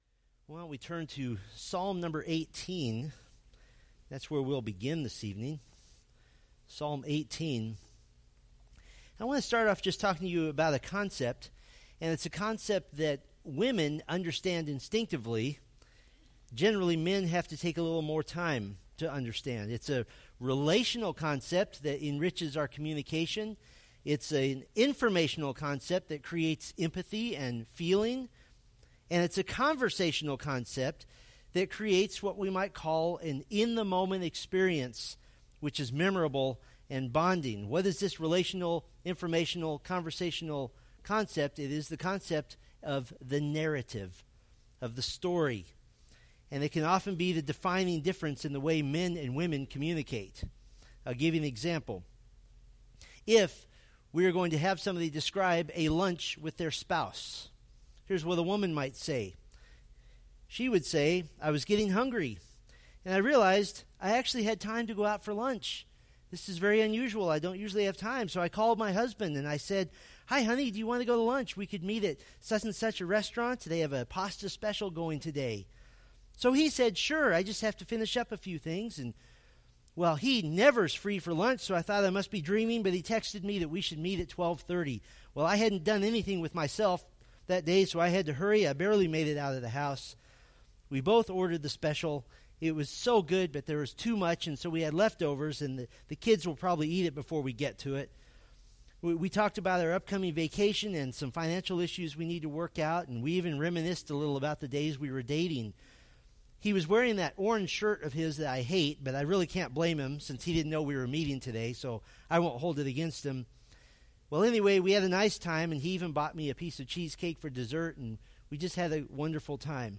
Preached June 11, 2017 from Selected Scriptures